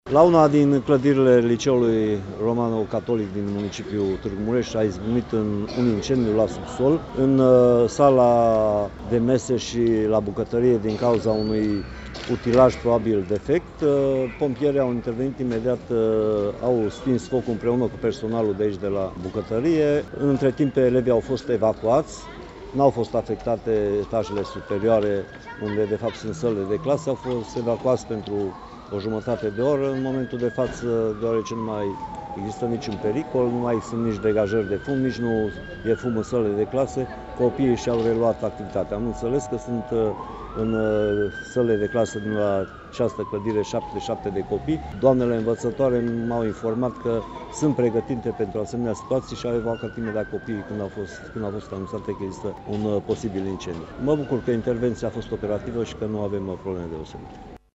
La această oră elevii și-au reluat cursurile și nu mai există nici un fel de pericol, spune prefectul județului Mureș, Mircea Dușa: